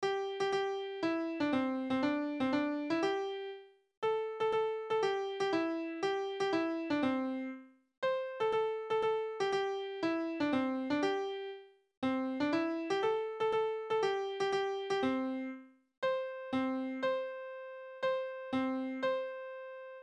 ※亀井小学校応援歌（記憶をもとに復元）.mp3